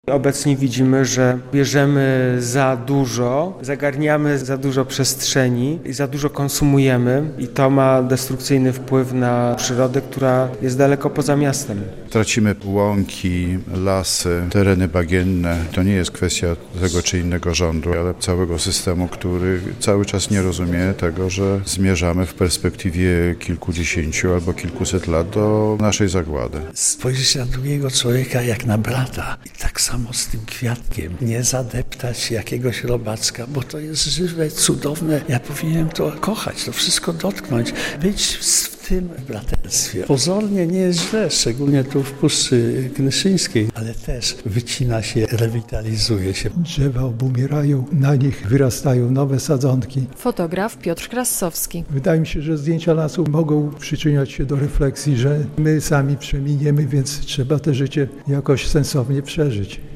O tym właśnie w sobotę (18.10) rozmawiano podczas spotkania z okazji Międzynarodowego Dnia Krajobrazu, które odbyło się w siedzibie Parku Krajobrazowego Puszczy Knyszyńskiej w Supraślu.